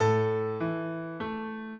piano
minuet7-3.wav